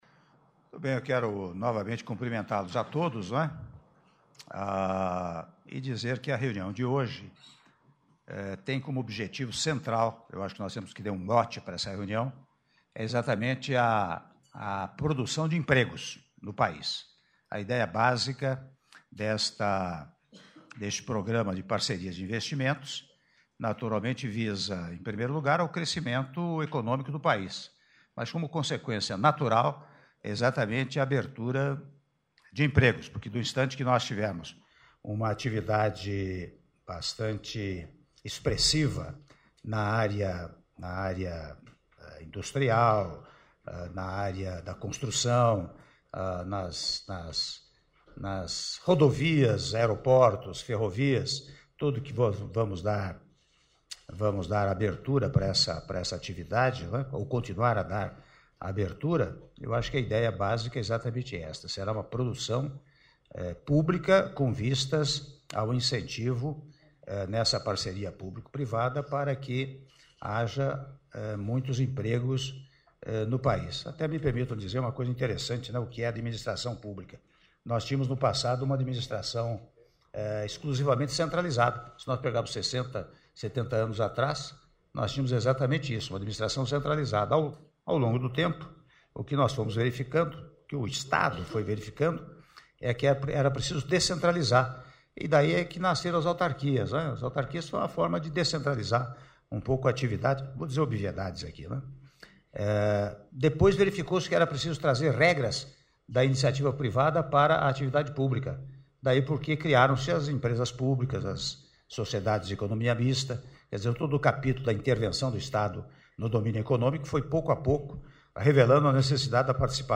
Áudio do discurso do Senhor Presidente da República, Michel Temer, na abertura da reunião Conselho do PPI - Brasília/DF- (04min13s)